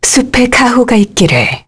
Lorraine-Vox_Victory_kr.wav